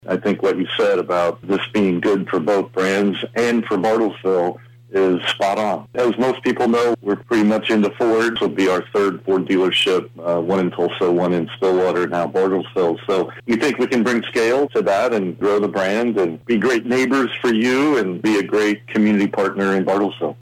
called into the CAR TALK program